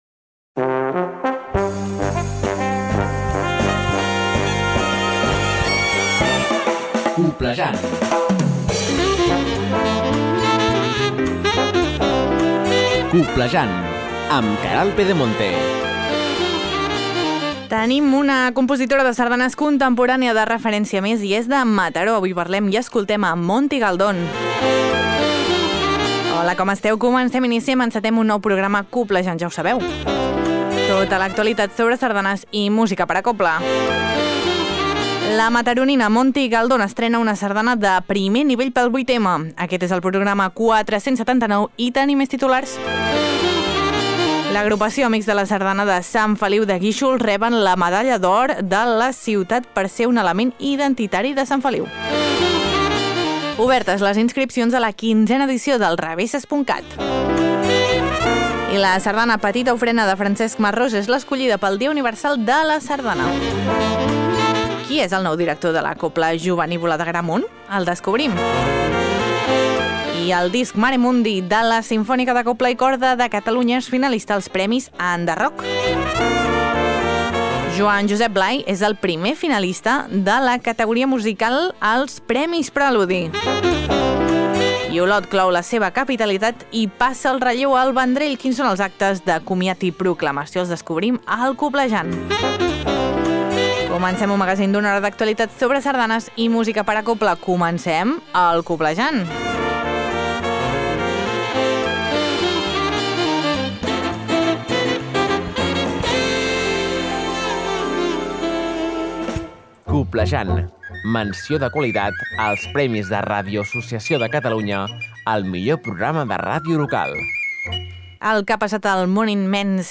Tot això i molt més a Coblejant, un magazín de Ràdio Calella Televisió amb l’Agrupació Sardanista de Calella per a les emissores de ràdio que el vulguin i s’emet arreu dels Països Catalans. T’informa de tot allò que és notícia al món immens de la sardana i la cobla.